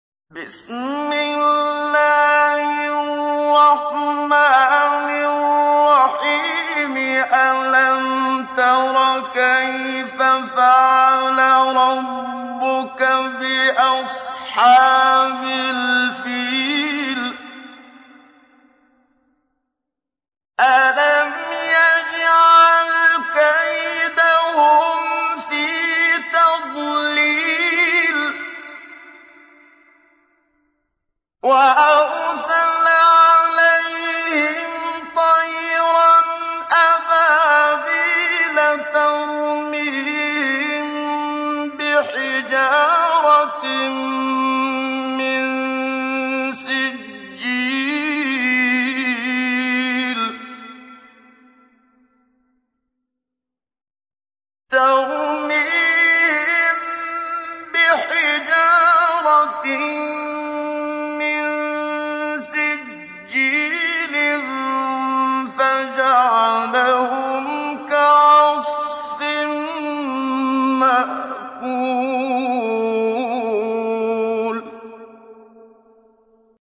تحميل : 105. سورة الفيل / القارئ محمد صديق المنشاوي / القرآن الكريم / موقع يا حسين